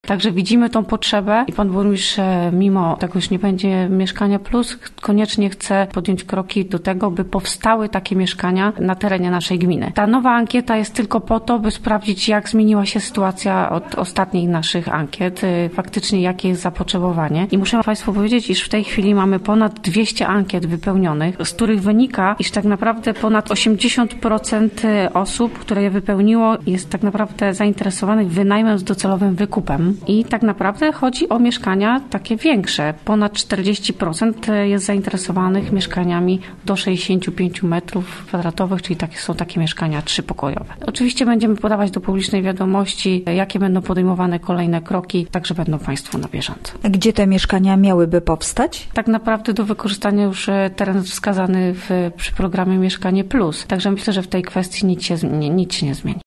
Na pytanie gdzie miałyby powstać nowe bloki wiceburmistrz Skotnicka odpowiada, że: teren do wykorzystania został już wskazany w programie „Mieszkanie plus”. Chodzi o działki, które usytuowane są przy ul. Sadowej w Wieluniu tam, gdzie obecnie powstaje żłobek.